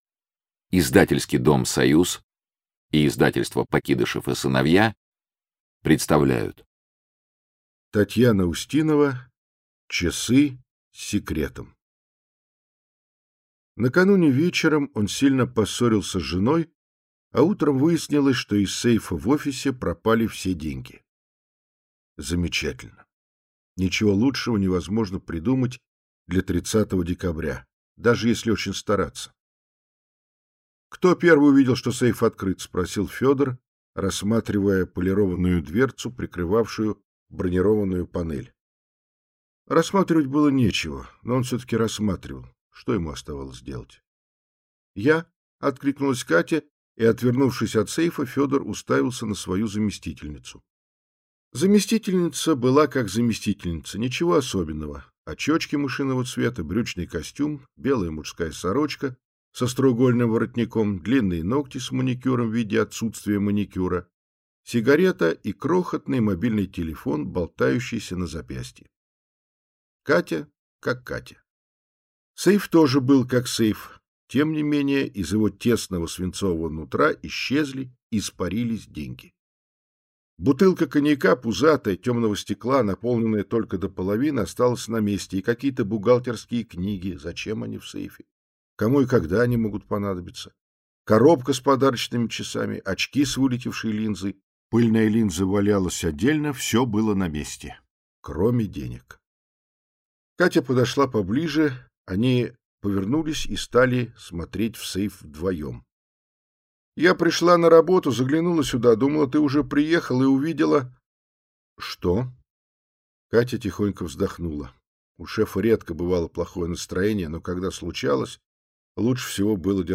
Аудиокнига Часы с секретом | Библиотека аудиокниг
Прослушать и бесплатно скачать фрагмент аудиокниги